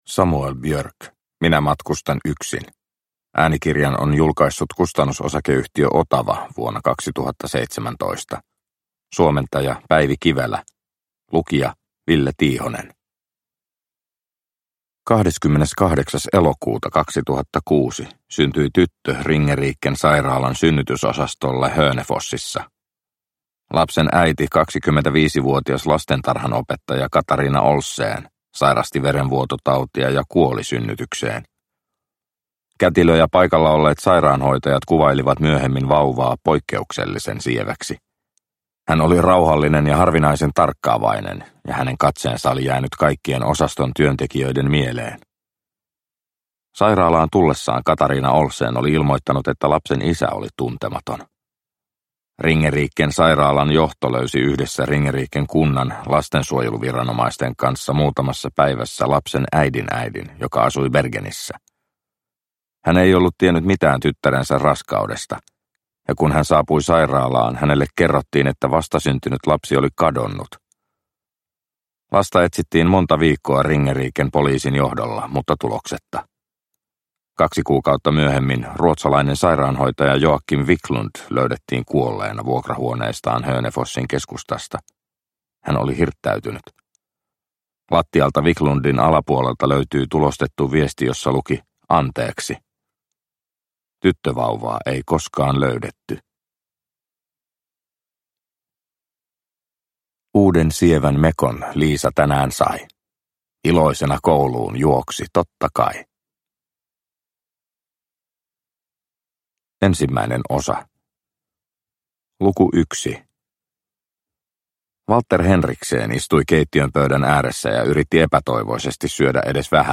Minä matkustan yksin – Ljudbok – Laddas ner